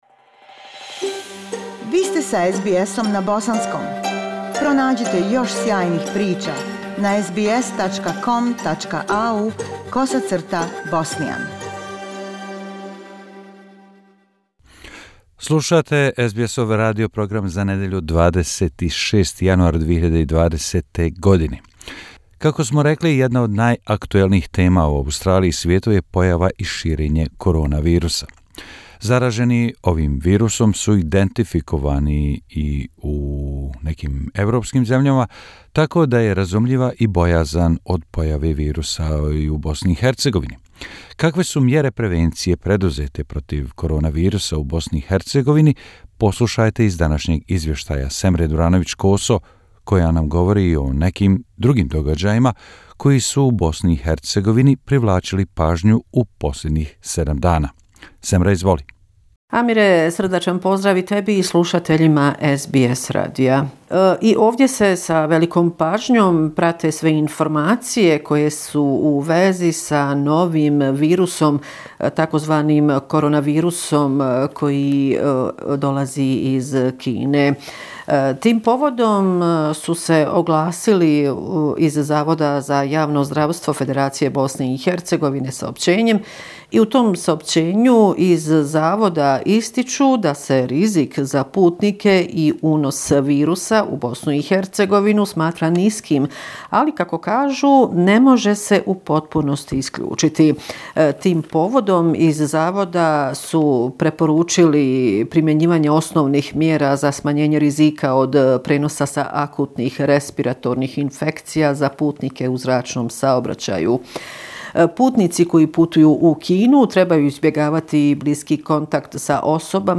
Bosnia and Herzegovina - affairs in the country for the last seven day, weekly report January 26, 202O